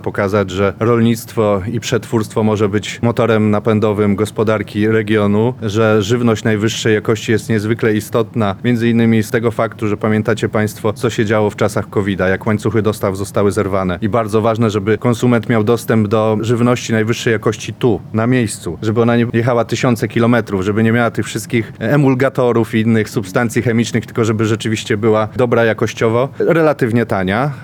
– Ich celem jest pokazanie dobrych praktyk – mówi wicemarszałek województwa lubelskiego Marek Wojciechowski.